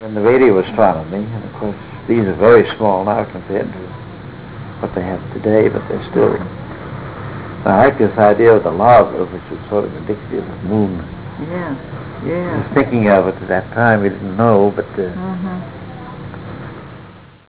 141Kb Ulaw Soundfile Hear Ansel Adams discuss this photo: [141Kb Ulaw Soundfile]